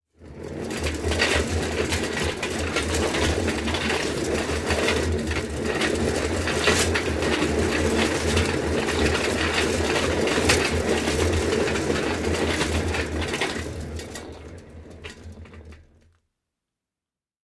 Звуки тележки, вагонетки
На этой странице собраны разнообразные звуки тележек и вагонеток: от легкого скрипа колес по асфальту до грохота тяжелых грузовых вагонеток.
Звук: везем на вагонетке уголь из шахты